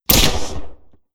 Grappling Hook Shoot Sound.wav